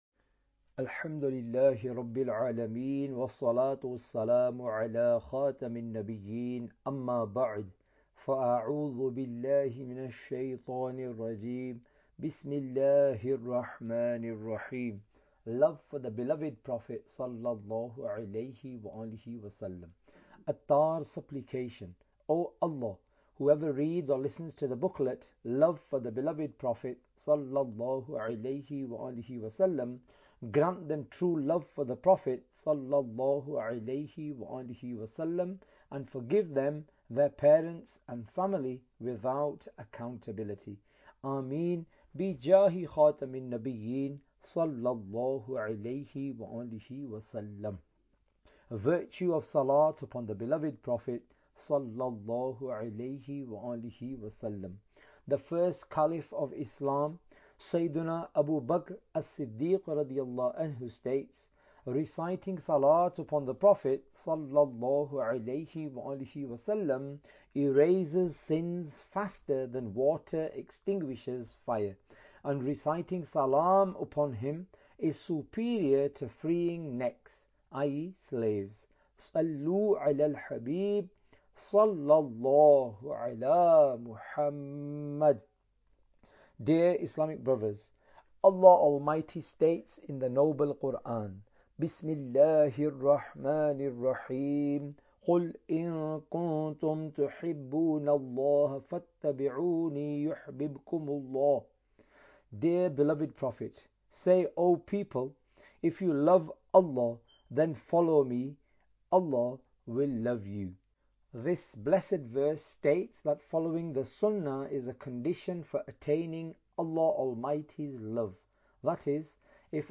Audiobook – Love for the Beloved Prophet صلی اللہ علیہ والہ وسلم (English)